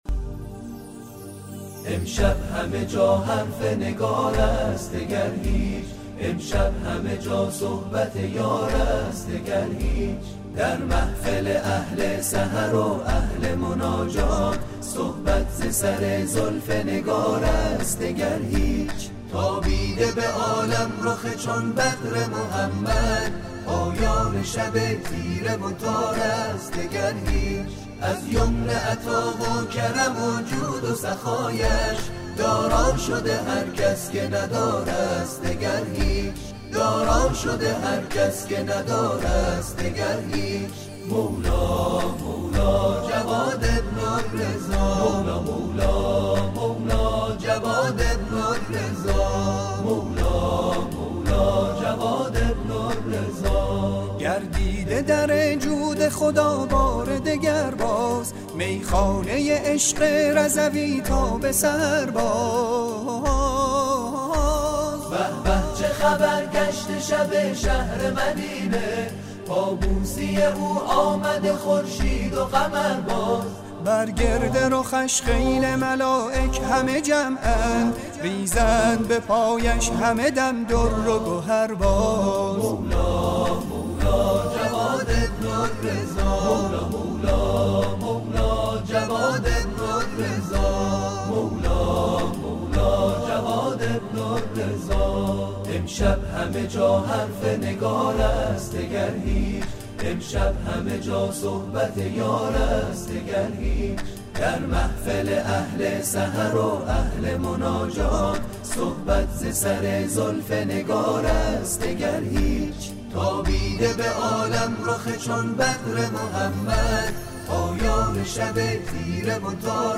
تواشیح میلاد امام جواد